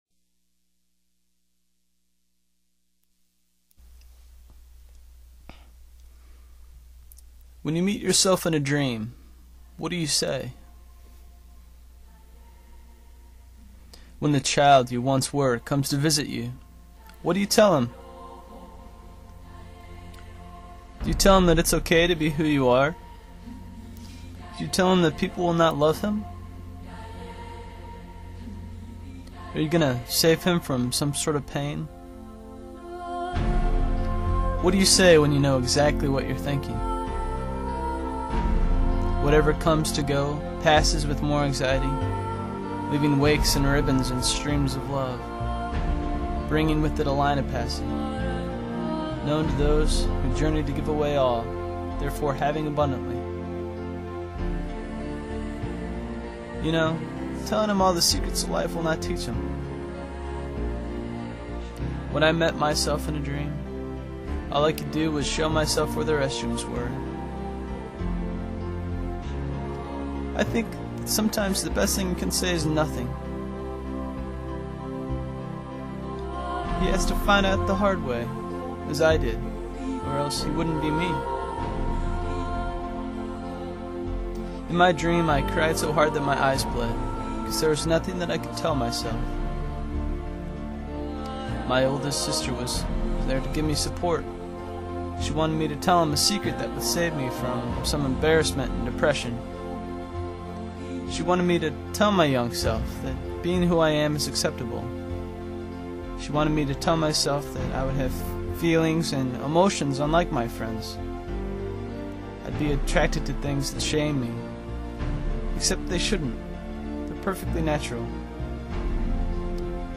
It was a very unique audio poem. We love the concept of the struggles we go through in life help us to become who we are today, and if we missed those, we might not be who we are today.